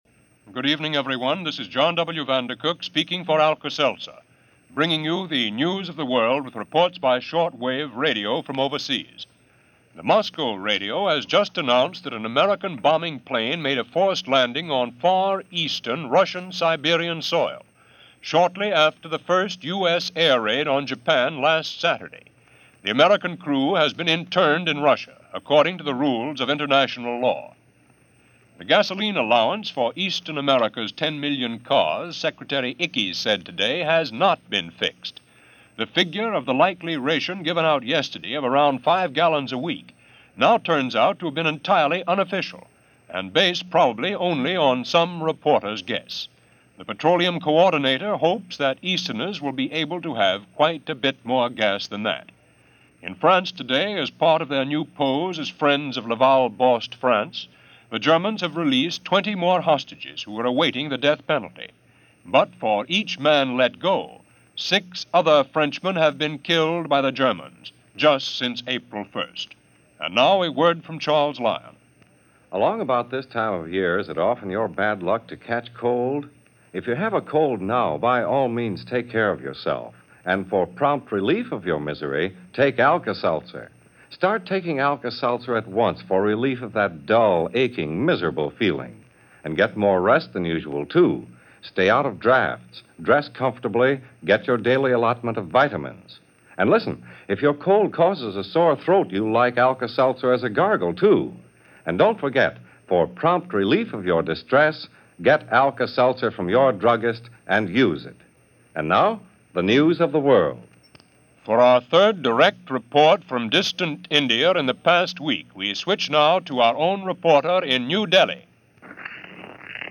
And news, which was taking place while this newscast was on, of a British commando raid underway on the French town of Boulogne.